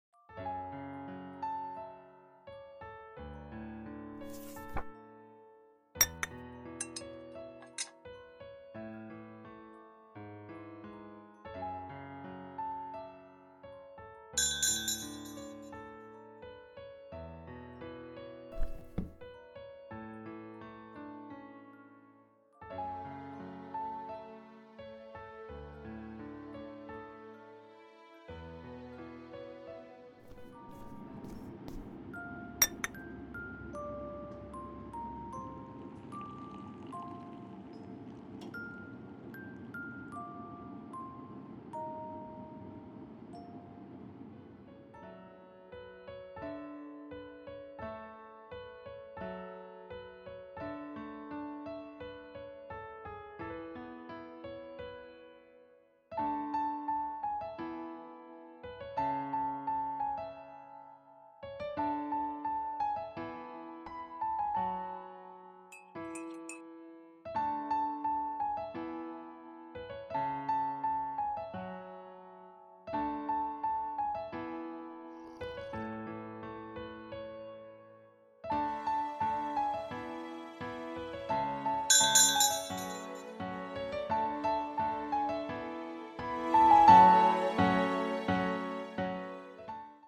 【声劇】.filo(bitter)